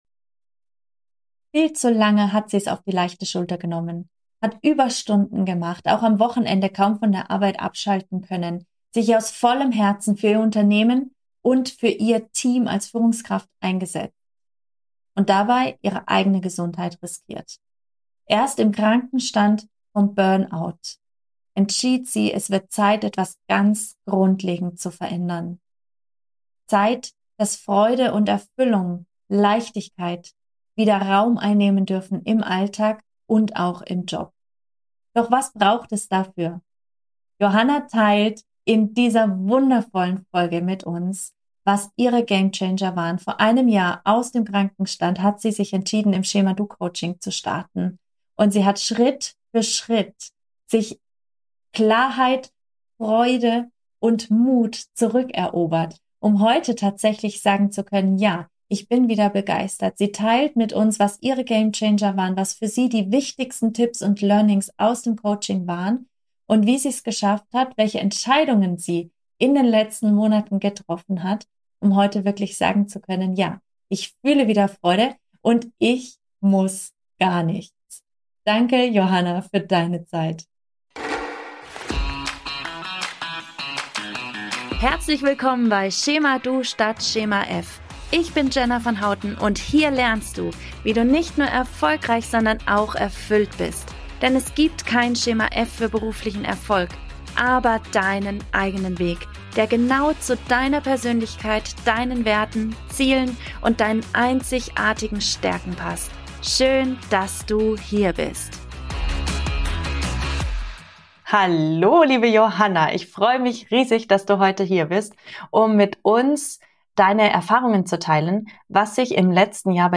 025 | Riskiere keinen Burnout! | Kundeninterview